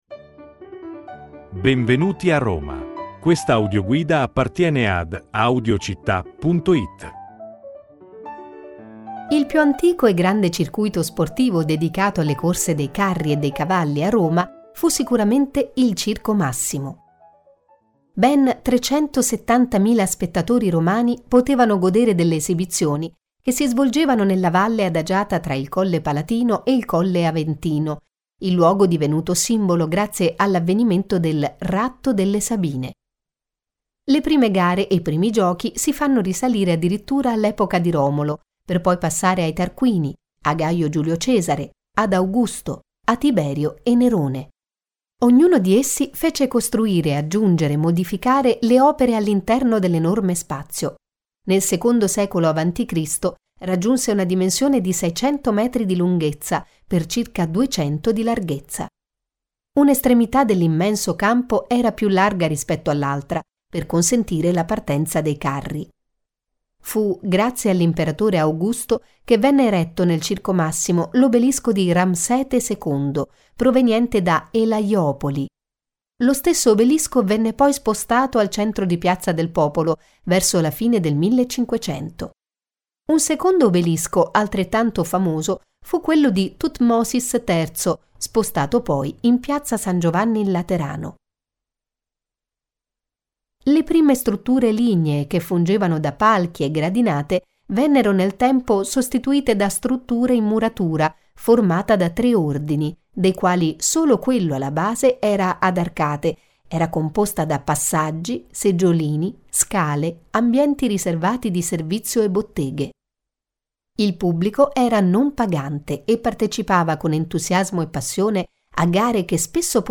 Audioguida Roma – Il Circo Massimo